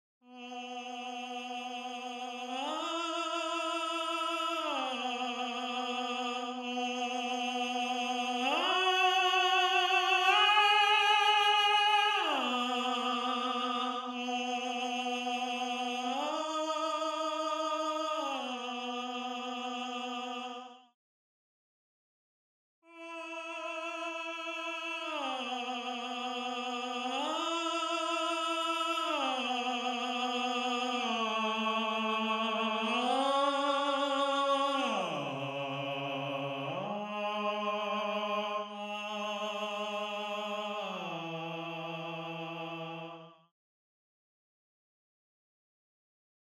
5. Voice 5 (Tenor/Tenor)
gallon-v8sp1-22-Tenor_0.mp3